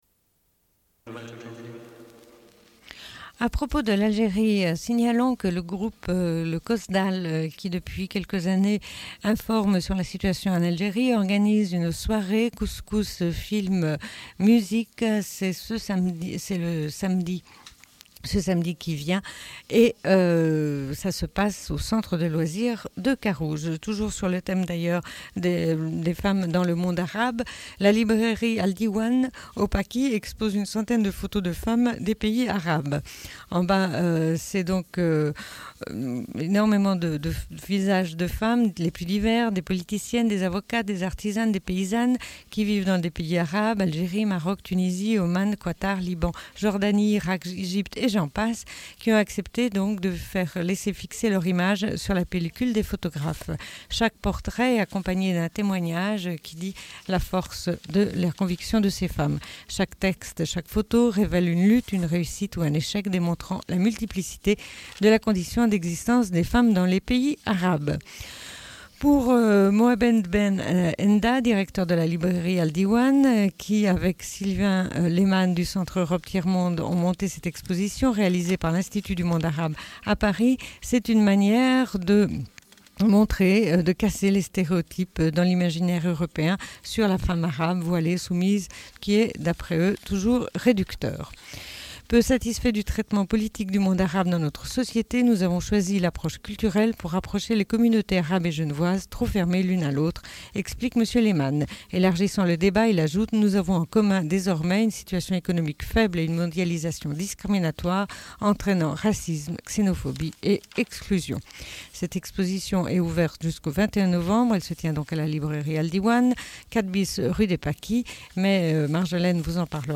Bulletin d'information de Radio Pleine Lune du 06.11.1996 - Archives contestataires